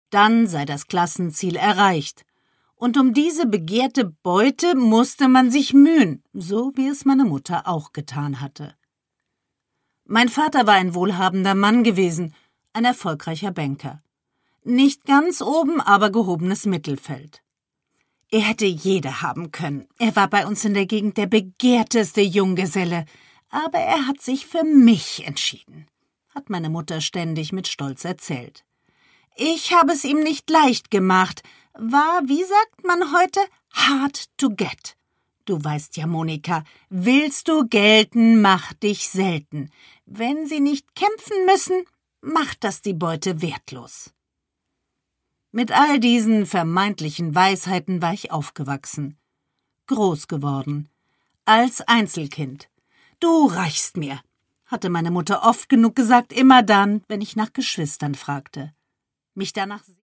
Produkttyp: Hörbuch-Download
Gelesen von: Susanne Fröhlich